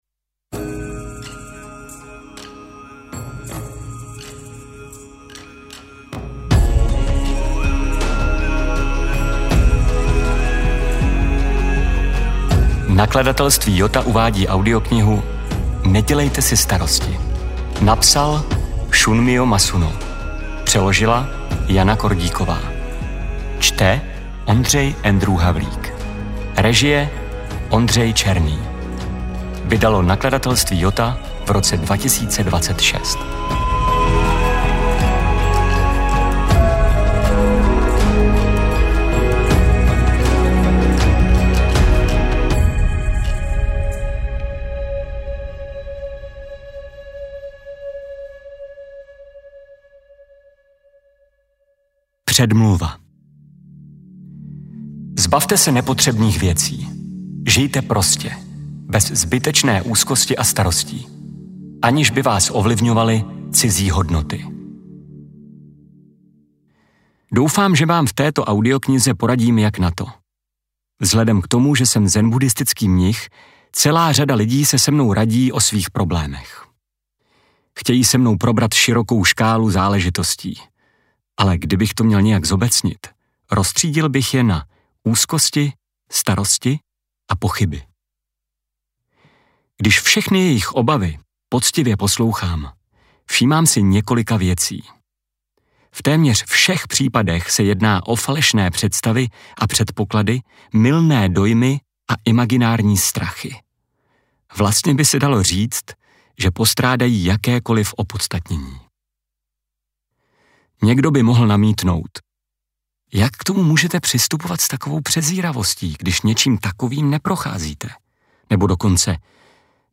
AudioKniha ke stažení, 49 x mp3, délka 3 hod. 57 min., velikost 213,7 MB, česky